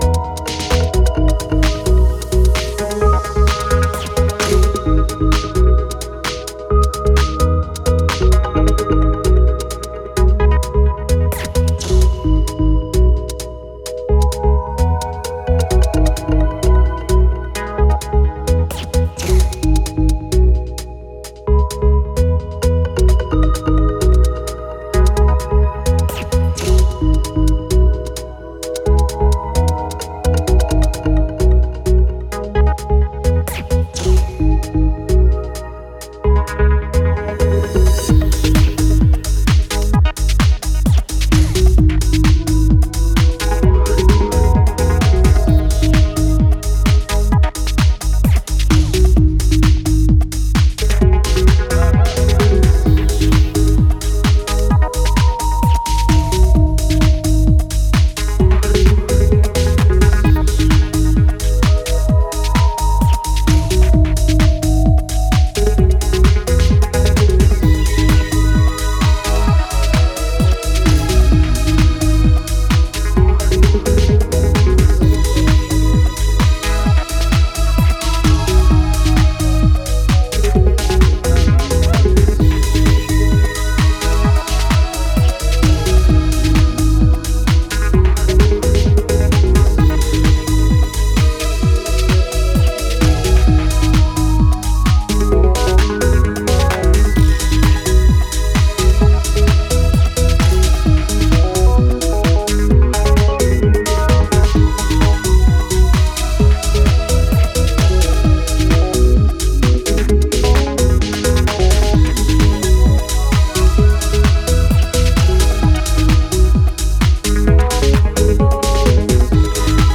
Hard-hitting grooves and pure nostalgia guaranteed.